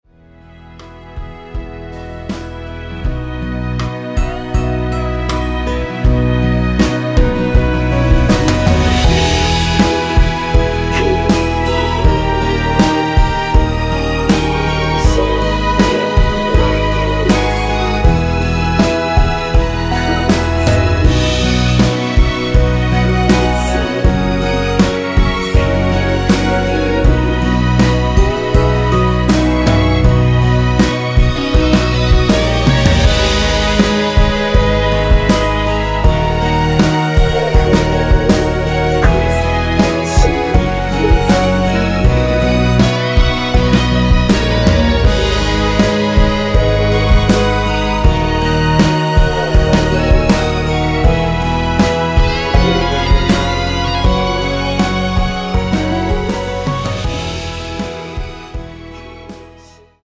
원키에서(+5)올린 멜로디와 코러스 포함된 MR입니다.(미리듣기 확인)
앞부분30초, 뒷부분30초씩 편집해서 올려 드리고 있습니다.
중간에 음이 끈어지고 다시 나오는 이유는